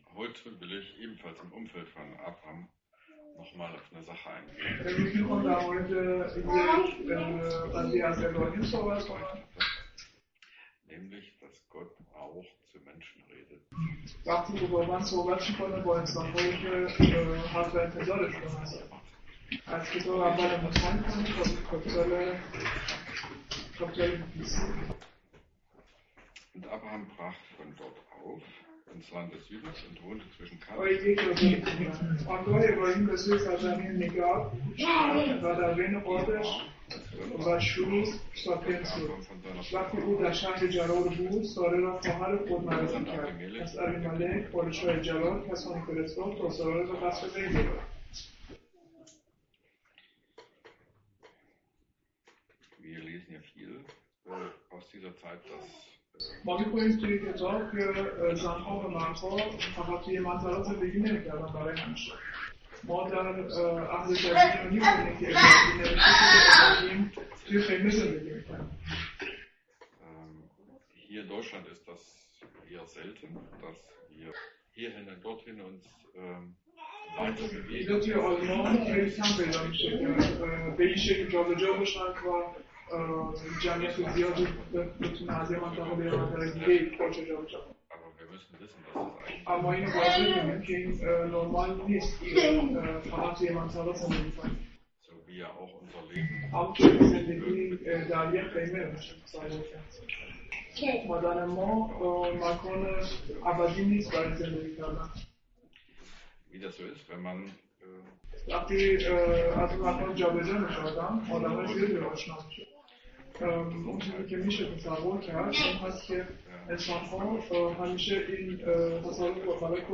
Mose 20 |Übersetzung in Farsi